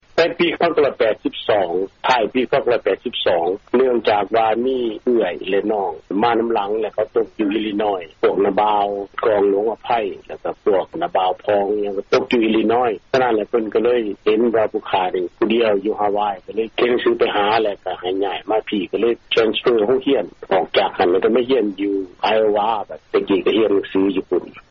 ທີ່ທ່ານຫາກໍໄດ້ຮັບຟັງຜ່ານໄປນັ້ນ ແມ່ນການໂອ້ລົມກັບລາວອາເມຣິກັນຄອບຄົວນຶ່ງ ໃນເຂດເມືອງໂທເລໂດ ລັດໂອຮາຍໂອ.